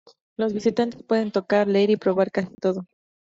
Pronounced as (IPA) /toˈkaɾ/